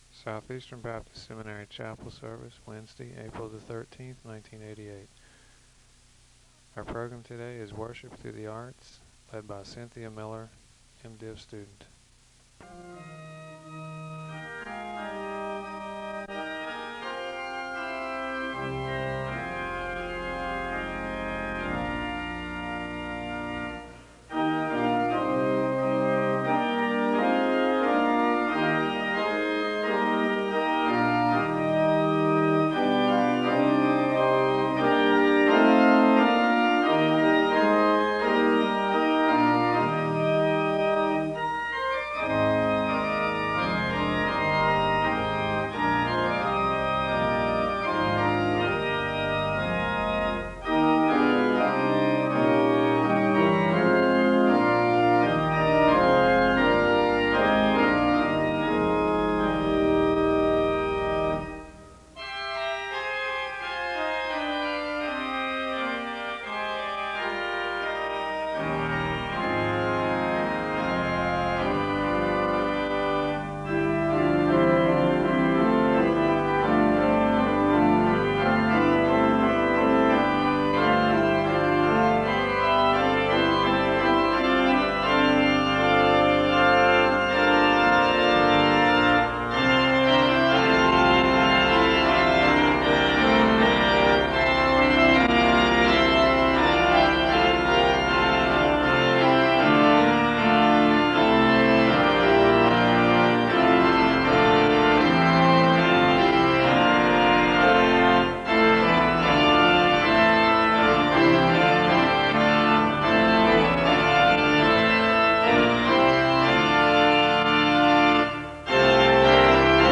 The service begins with organ music (0:00-2:29). An introduction to the service is given as well as an explanation for the banners hanging in the chapel (2:30-5:33). There is a call to worship (5:34-6:26). Prayer concerns are shared and there is a moment of prayer (6:27-10:32). There is a Scripture reading from Genesis (10:33-11:52).
The service closes with a charge to the congregation (23:04-23:34).